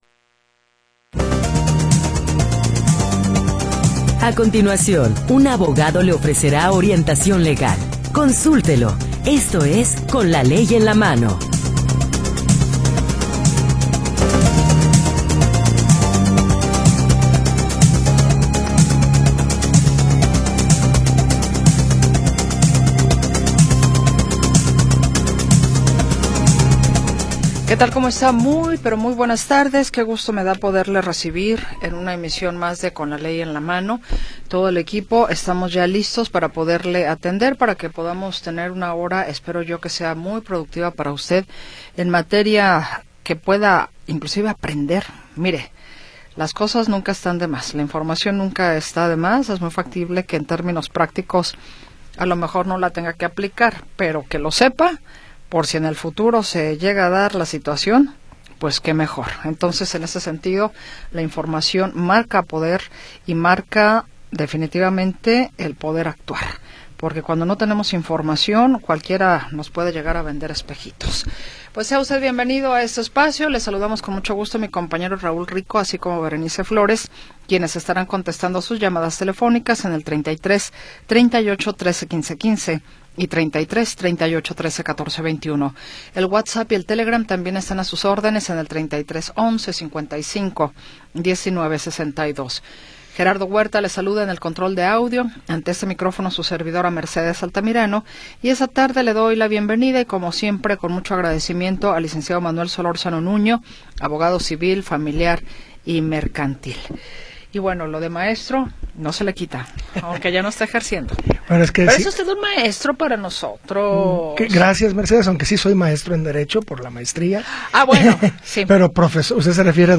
Programa transmitido el 29 de Julio de 2025.